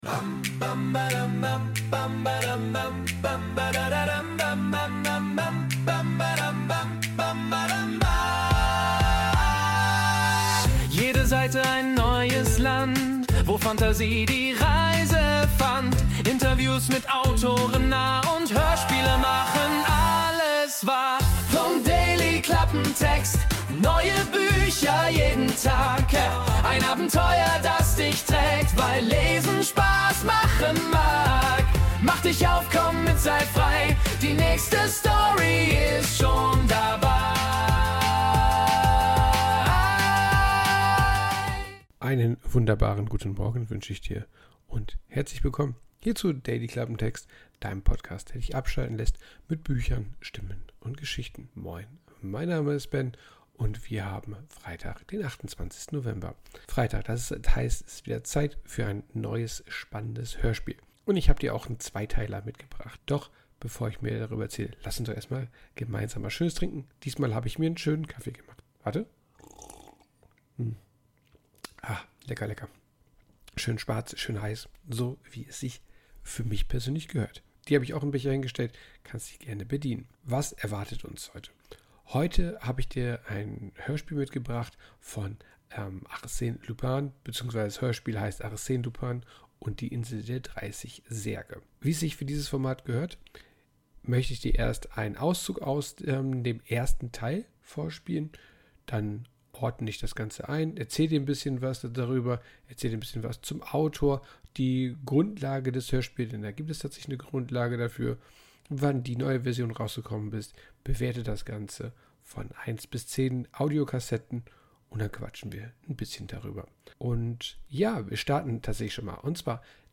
Folge 294: Arsène Lupin und die Insel der 30 Särge | Hörspielreview ~ Dailyklappentext Podcast